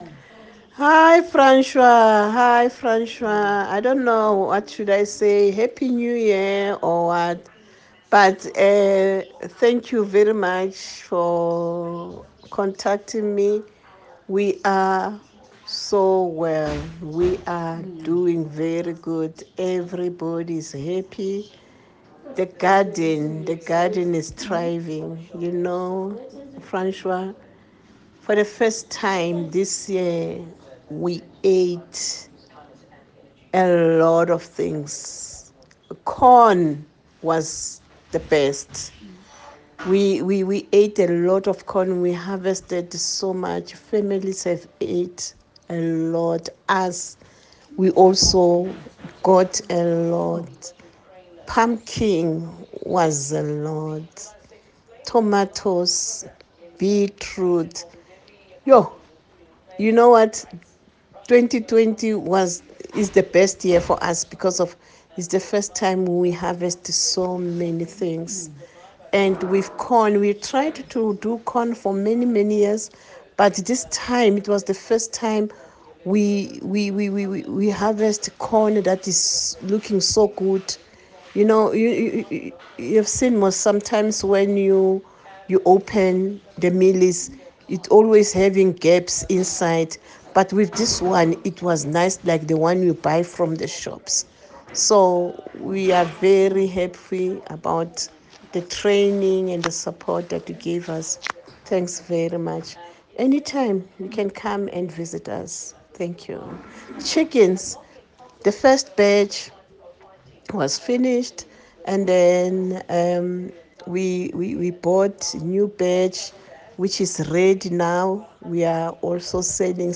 Audio testimony.